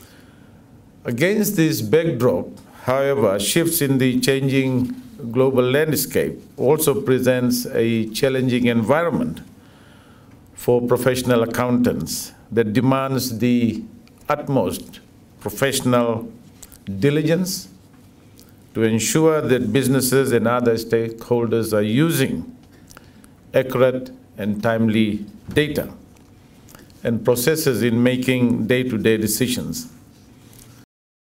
Speaking at the CPA Australia Fiji Symposium in Suva, Professor Prasad says business management depends on accurate data as it helps make sound future projections.